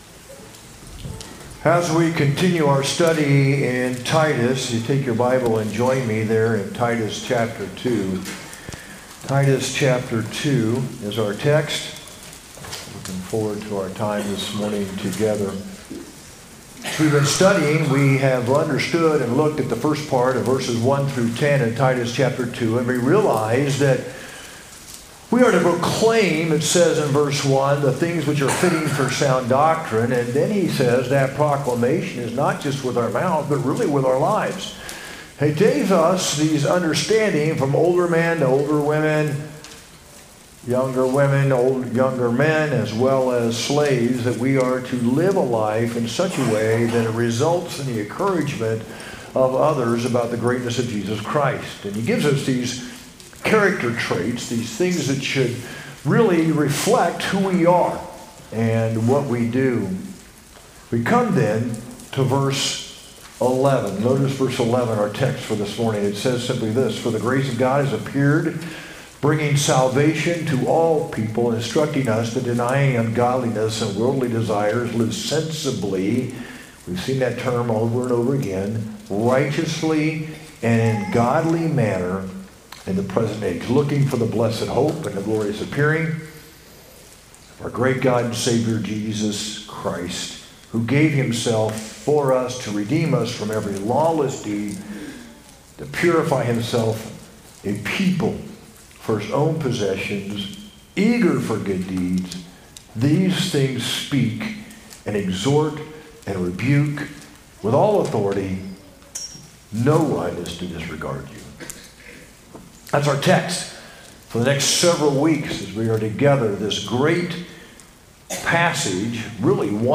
sermon-7-13-25.mp3